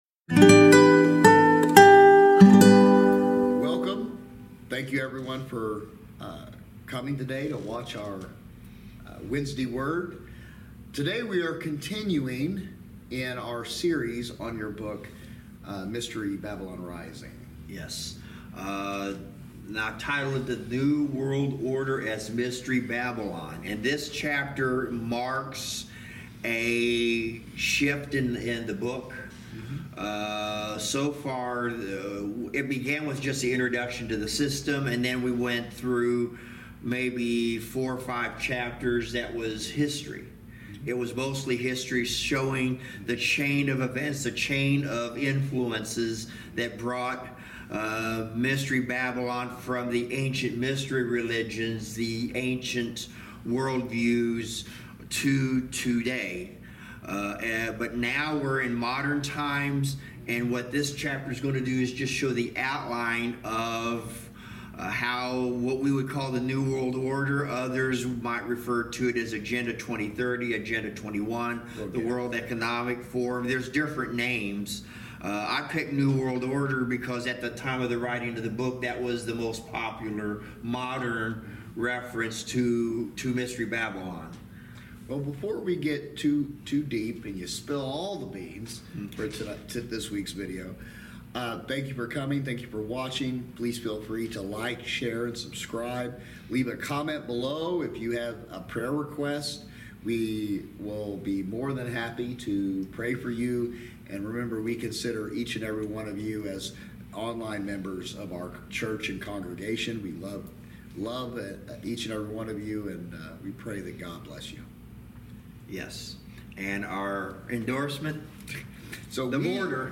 Service Type: Wednesday Word Bible Study